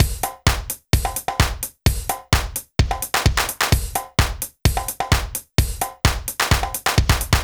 BAL Beat - Mix 1.wav